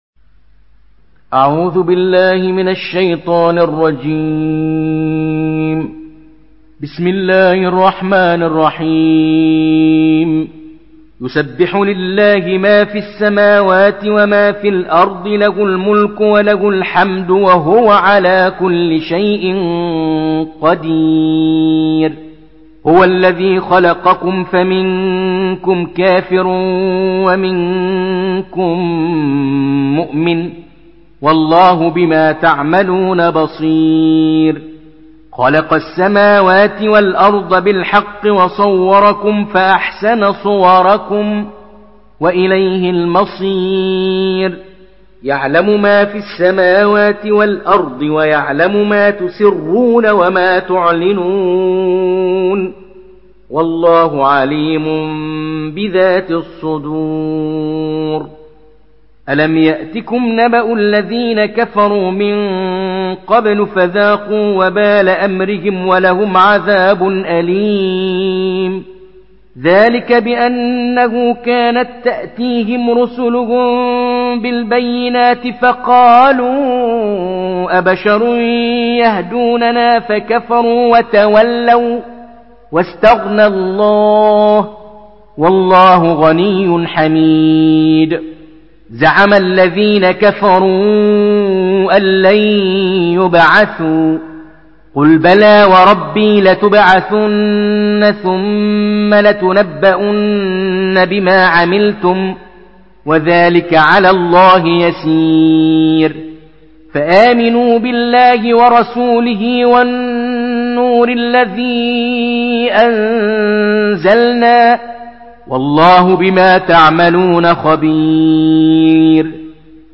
مرتل حفص عن عاصم